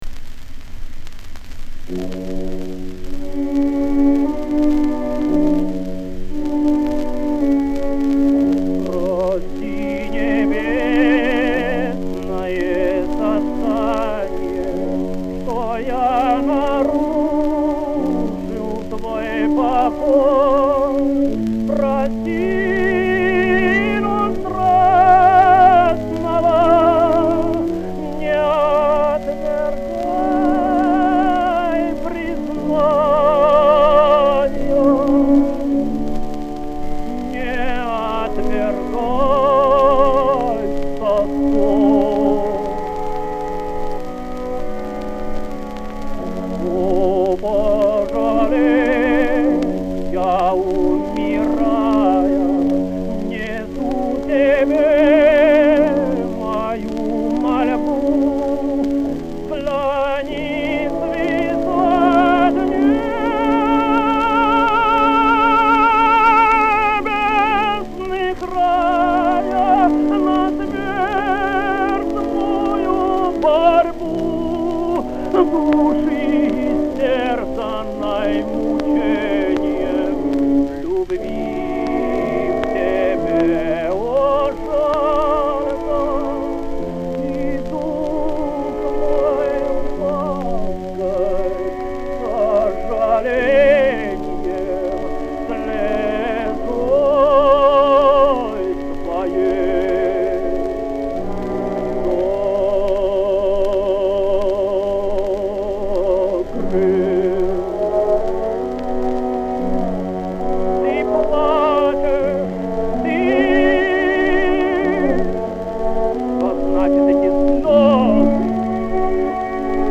Vintage DIY audio articles & audiofile shellac and vinyl remastering